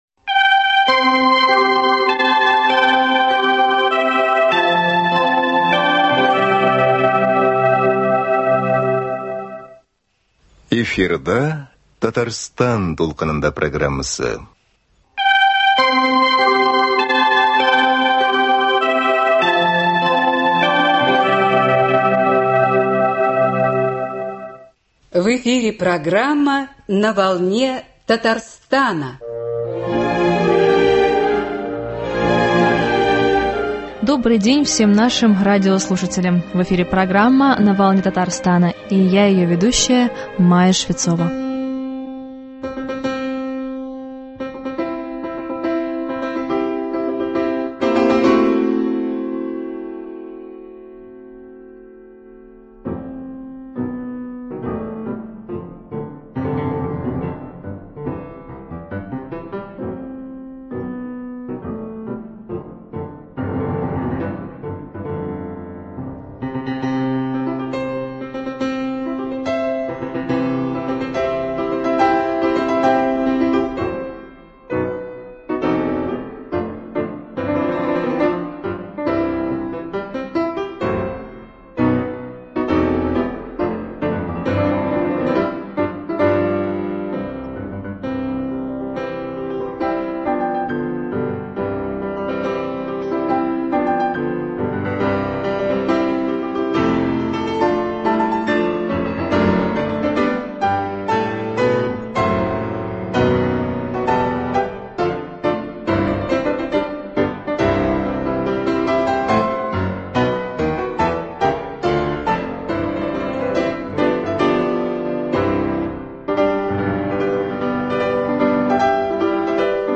Интервью с пианистом Валерием Кулешовым.